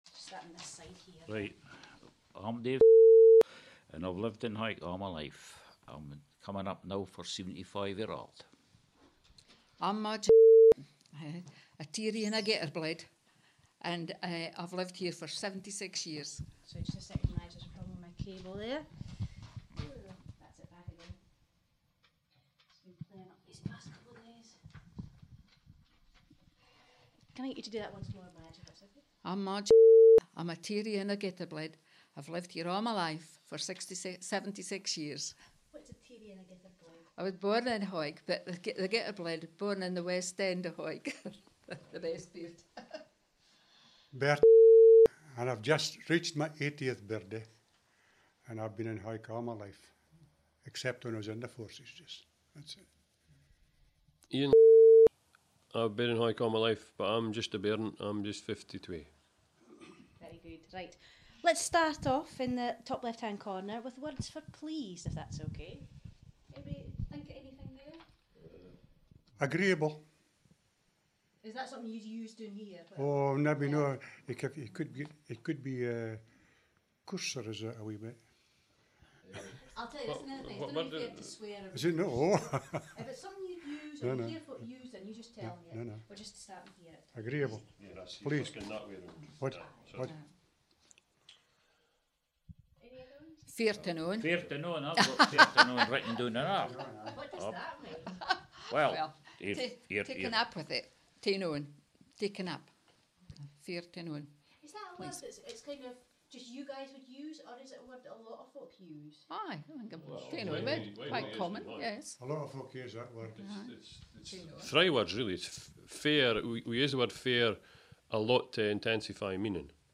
SCOTS - BBC Voices Recording: Hawick